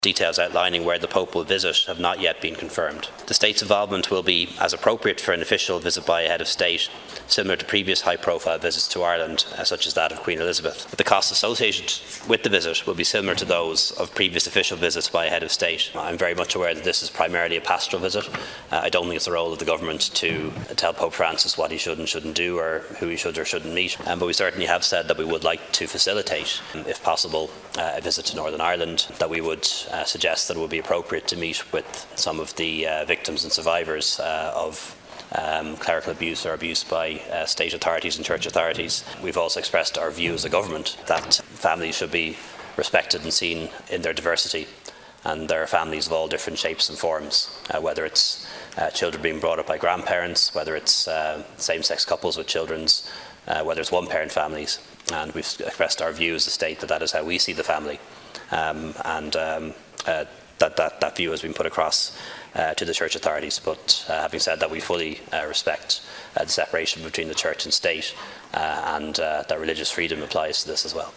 Leo Varadkar was asking Dail questions from a number of TDs, including Cavan Monaghan Deputy Brendan Smith, who asked if the government was actively encouraging Pope Francis to visit the North and the border region.